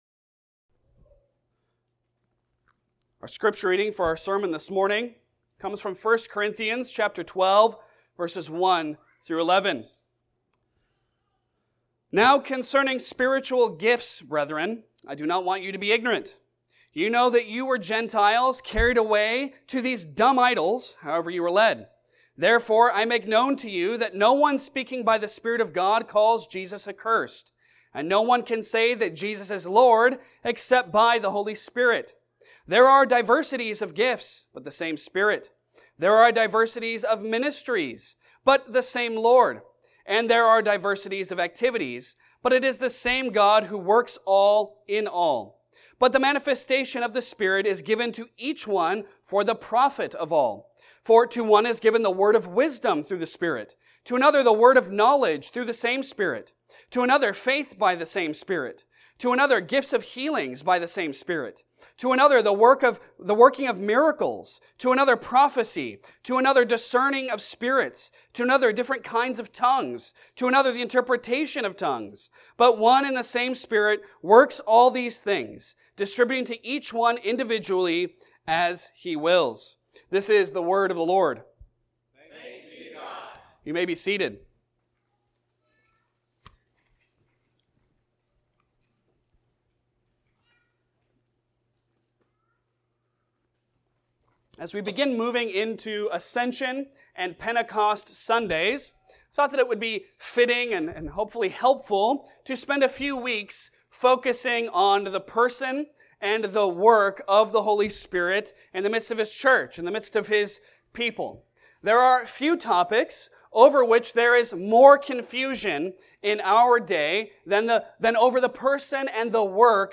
Passage: 1 Corinthians 12:1-11 Service Type: Sunday Sermon